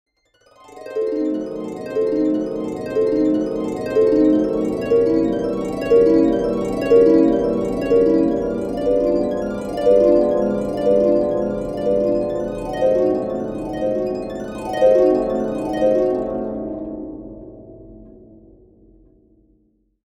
Magical Ethereal Harp Arpeggio Sound Effect
Description: Magical ethereal harp arpeggio sound effect. Create magical atmospheres with this stunning ethereal harp arpeggio musical motif.
Genres: Sound Logo
Magical-ethereal-harp-arpeggio-sound-effect.mp3